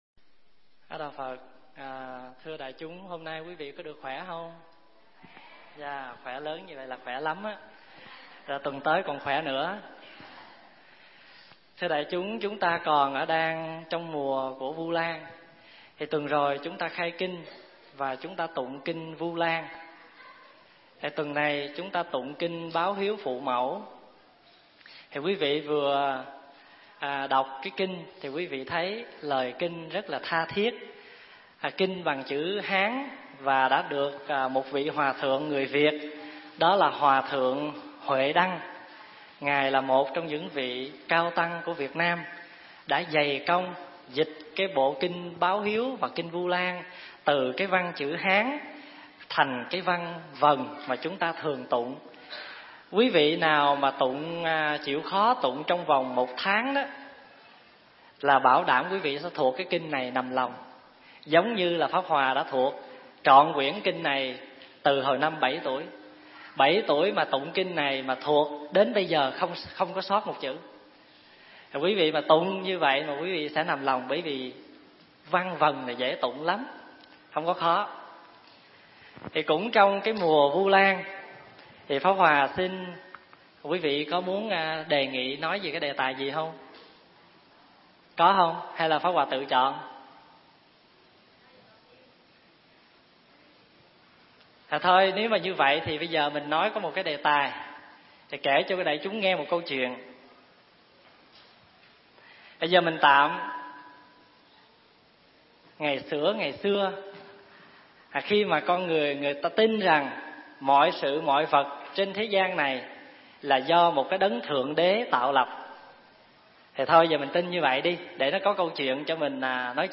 Tải mp3 Thuyết Giảng 70 Năm Cuộc Đời
thuyết giảng tại Tu Viện Trúc Lâm, Canada, năm 2003